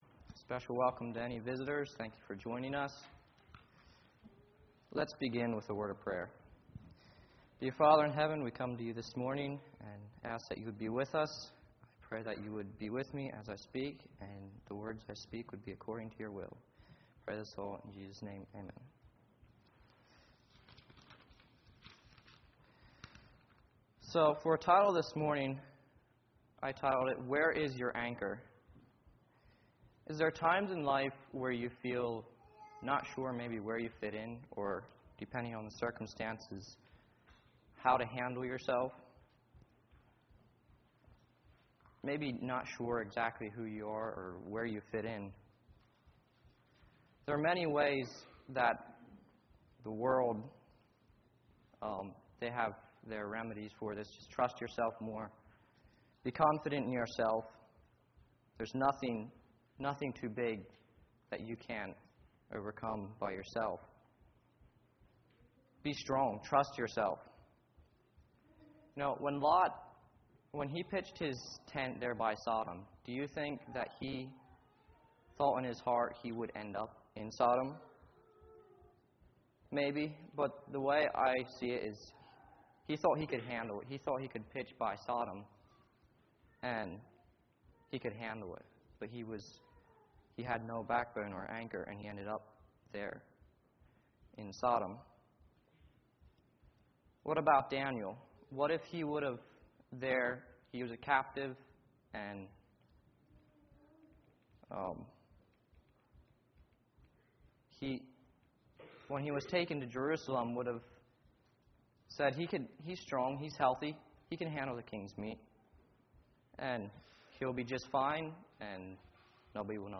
All Sermons Where Is Your Anchor?